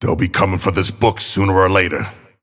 Abrams voice line - They'll be coming for this book sooner or later.